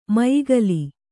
♪ mayigali